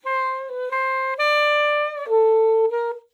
sax-phrase-short.wav